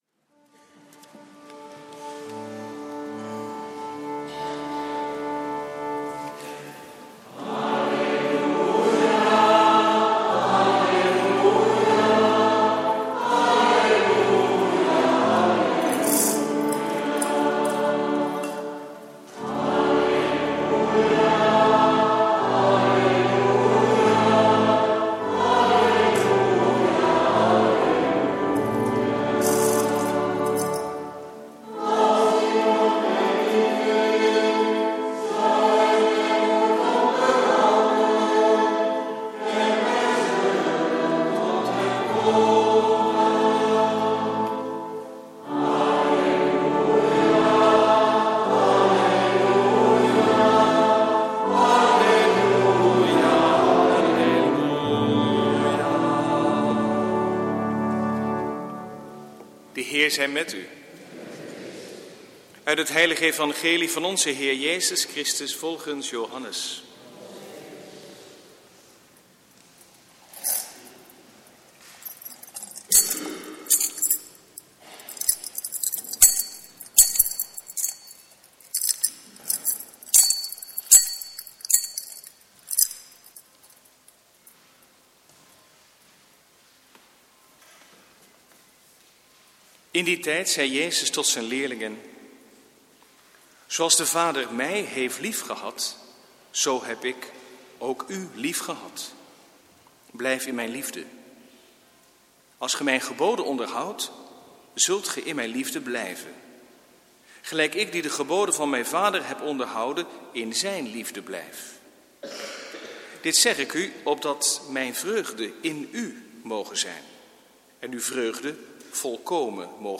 Preek 6e zondag van Pasen, jaar B, 9/10 mei 2015